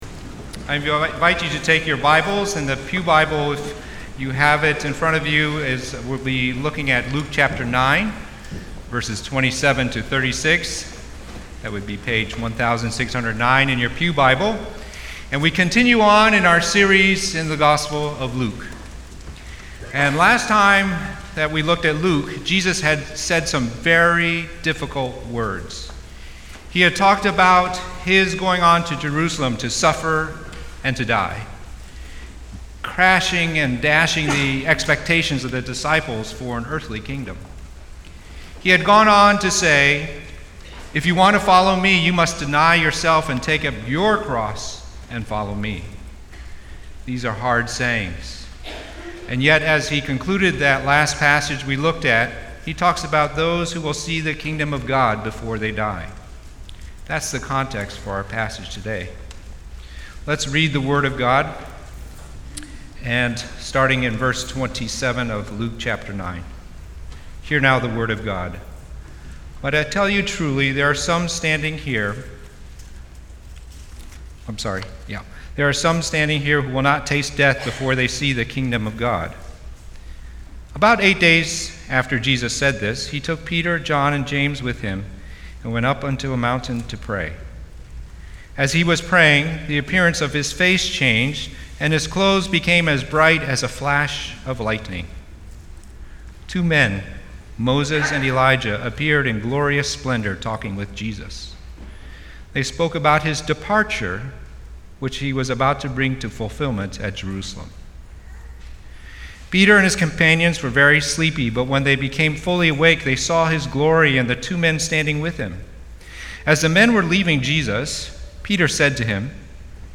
From Series: "Sunday Sermons"